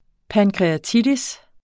pankreatitis substantiv, fælleskøn også i formen: pancreatitis Bøjning -sen Udtale [ pankʁεaˈtidis ] Oprindelse af pankreas , og -itis Betydninger betændelse i bugspytkirtlen, pankreas der er ikke meget sul på kroppen.